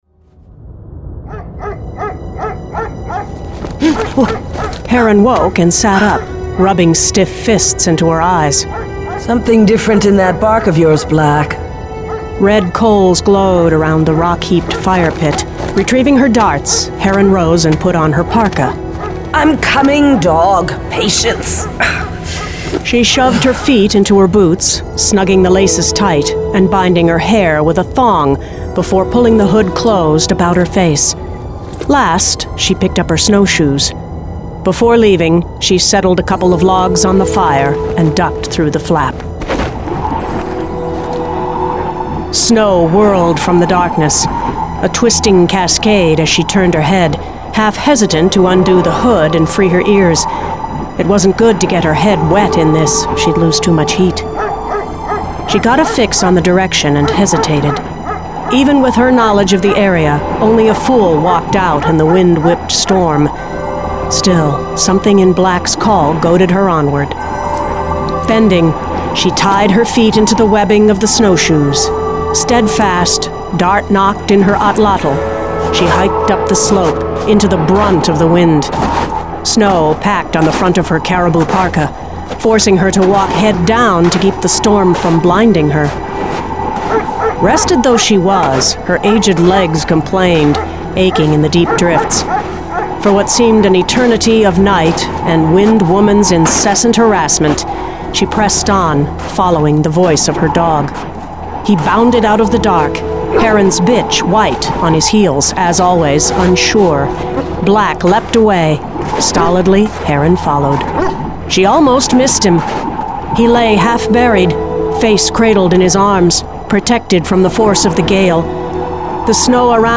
Full Cast. Cinematic Music. Sound Effects.